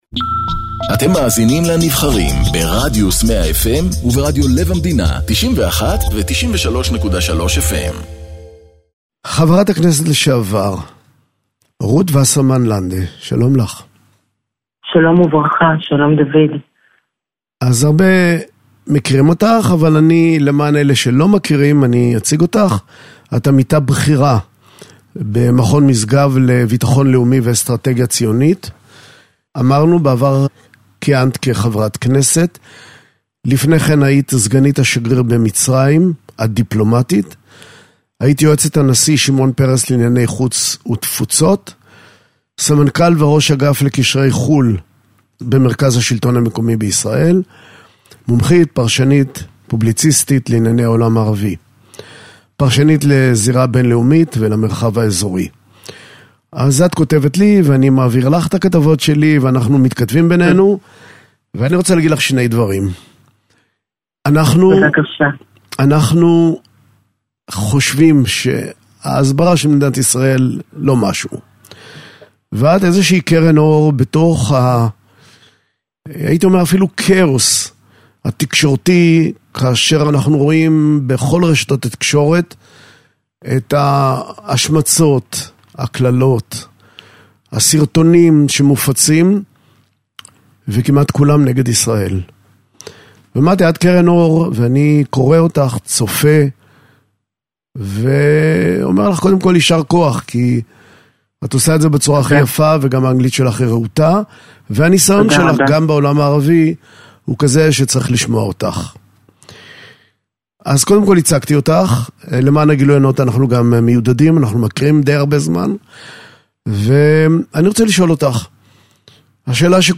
מראיין את חברת הכנסת לשעבר, רות וסרמן- לנדה